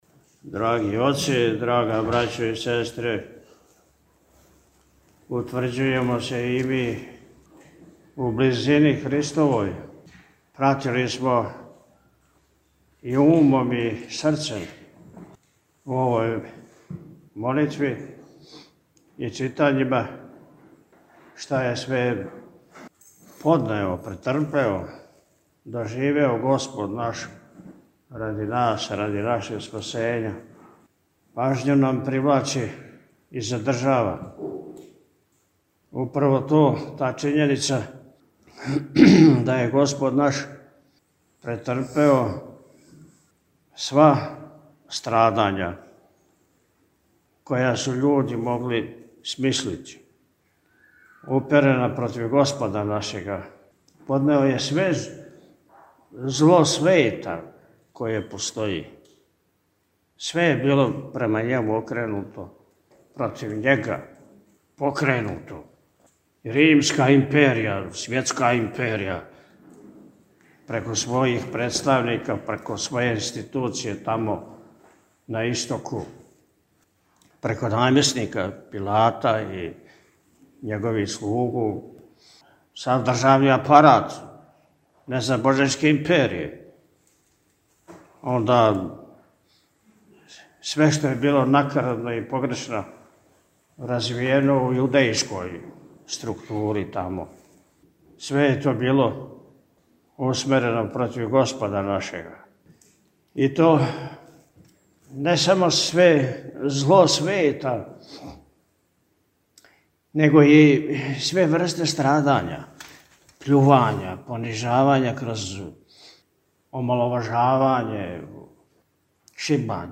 На Велики Четвртак, 17. априла 2025. године, Његово Високопреосвештенство Архиепископ и Митрополит милешевски г. Атанасије служио је у Вазнесењском храму манастира Милешеве Јутрење Великог Петка са читањем дванаест Јеванђеља о страдањима Христовим.